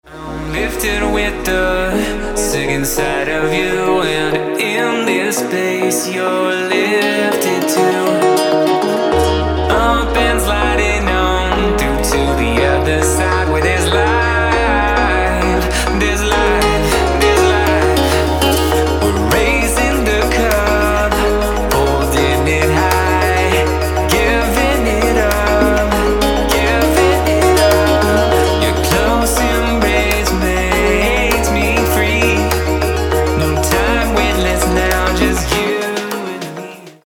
• Качество: 224, Stereo
поп
мужской вокал
deep house
dance
tropical house
vocal